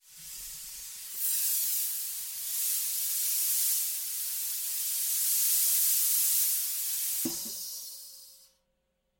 Звуки спускающегося колеса
звук скользящего колеса